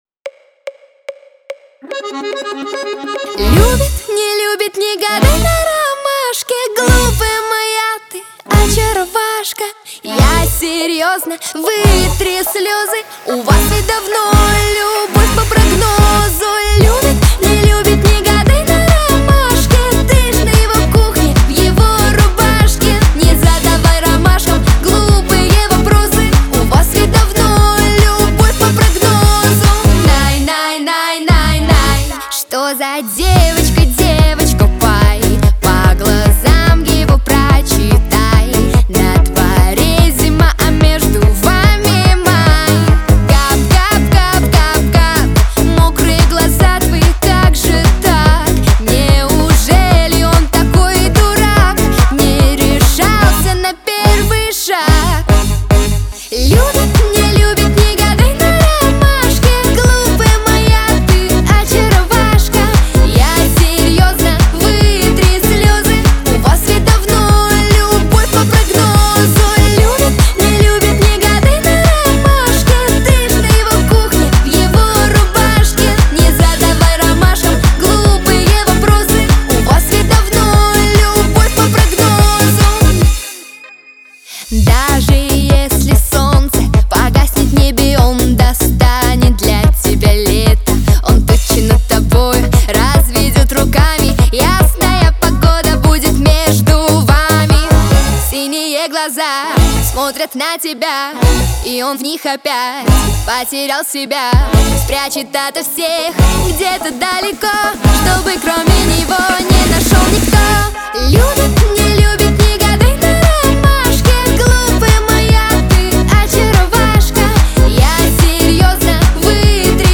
скачать Поп